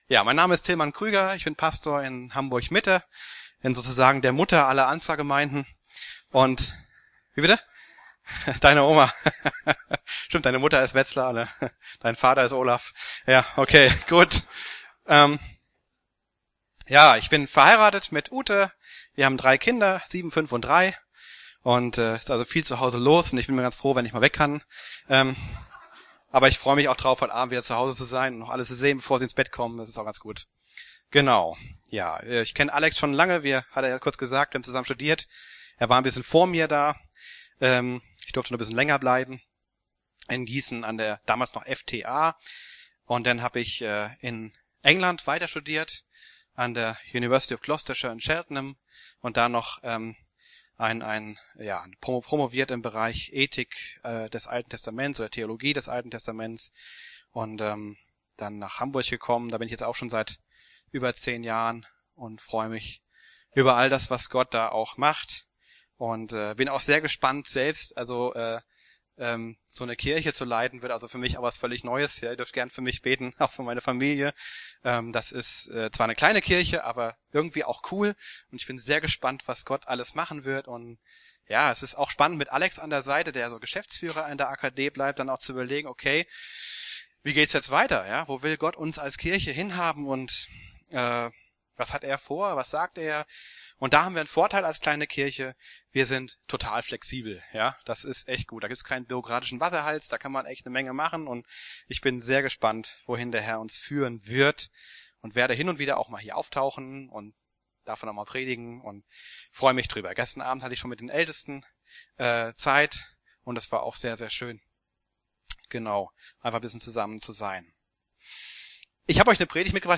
Marburger Predigten